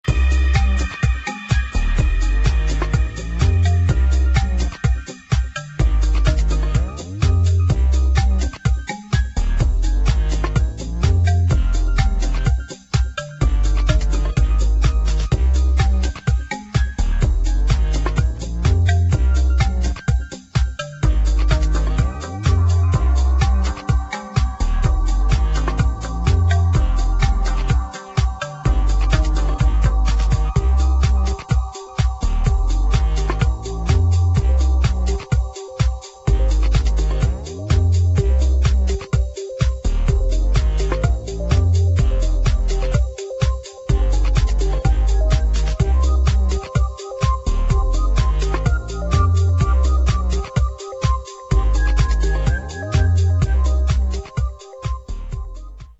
[ TECH HOUSE / PROGRESSIVE HOUSE ]